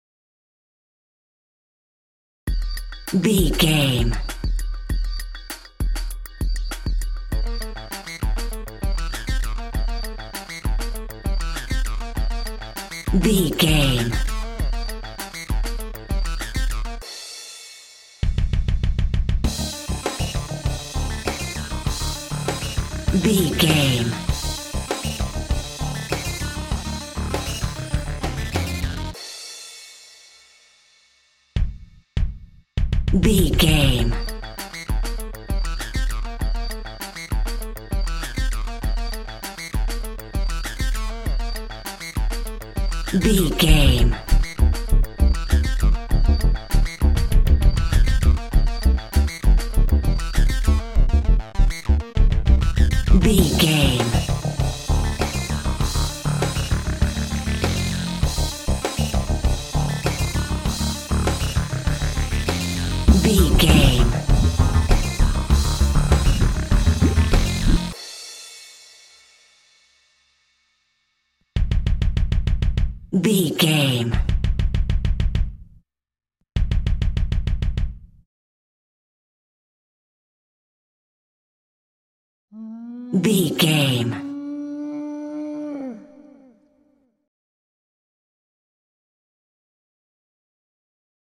1st Person Shooter Video Game Music.
Aeolian/Minor
electronic
techno
trance
drone
glitch
synth lead
synth bass